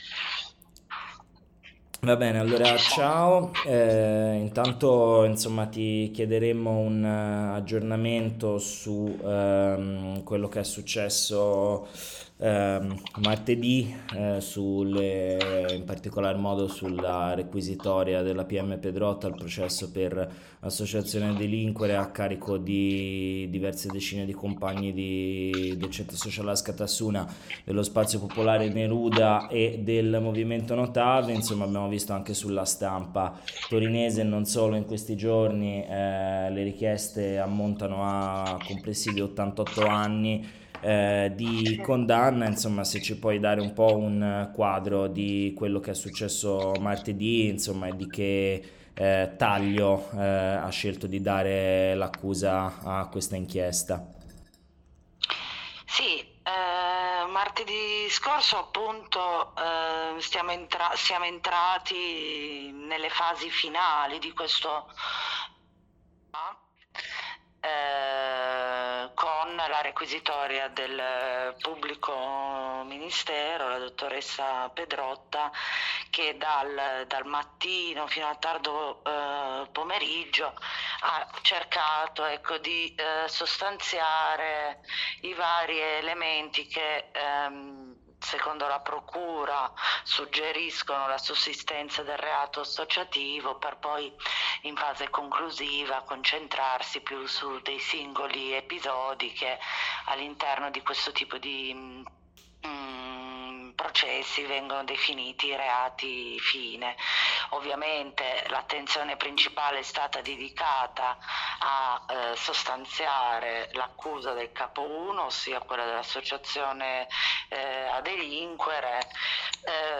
un’approfondita intervista